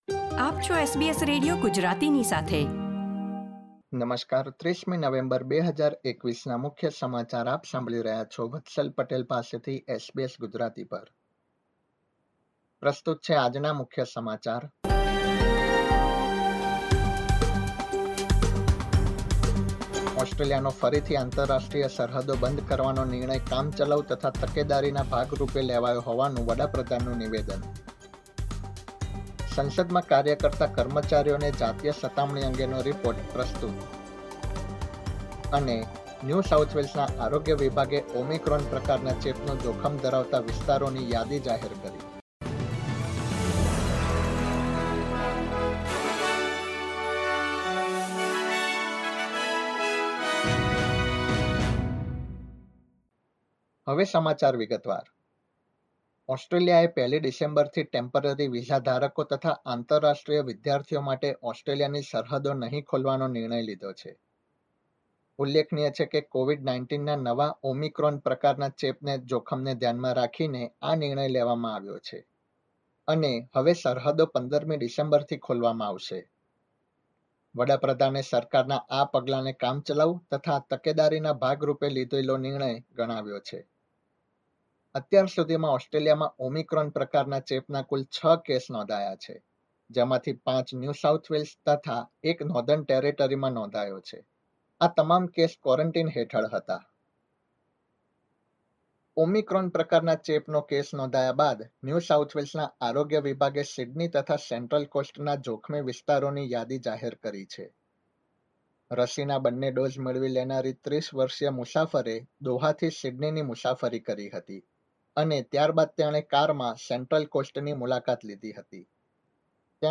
SBS Gujarati News Bulletin 30 November 2021